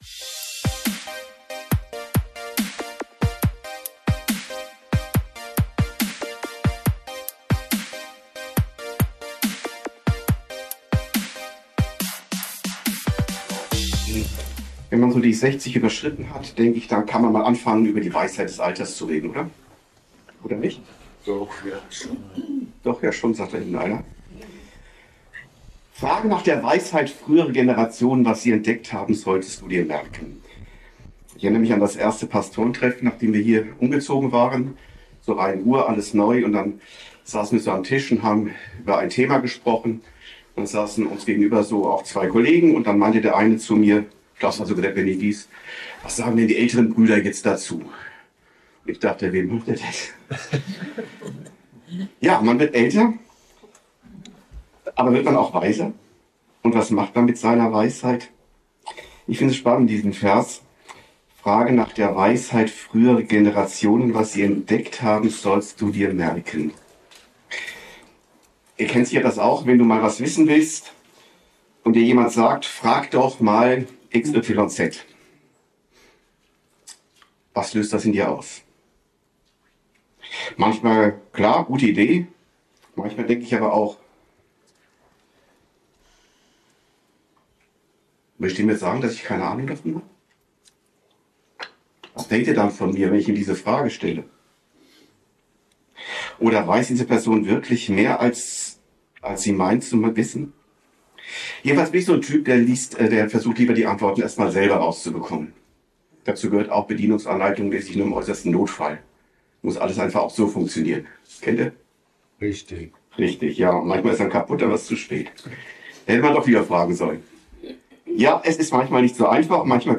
Die Weisheit des Alters ~ Predigten u. Andachten (Live und Studioaufnahmen ERF) Podcast